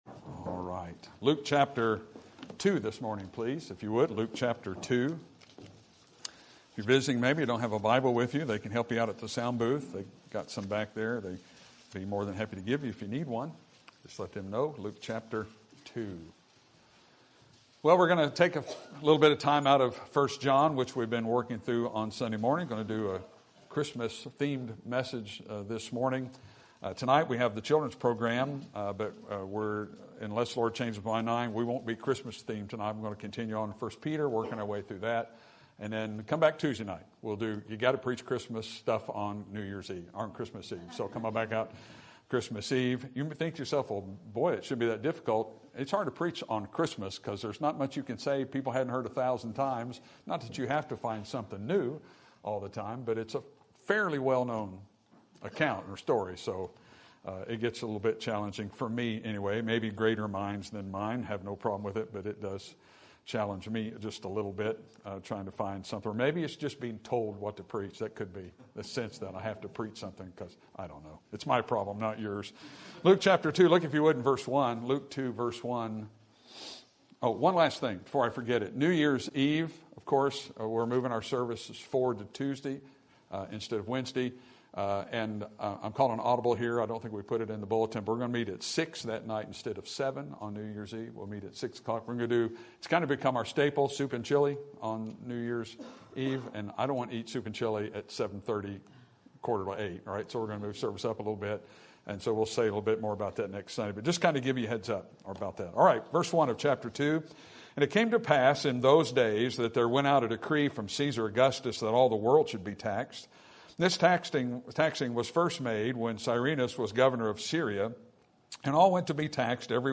Sermon Topic: General Sermon Type: Service Sermon Audio: Sermon download: Download (22.84 MB) Sermon Tags: Luke Shepherds Angel Jesus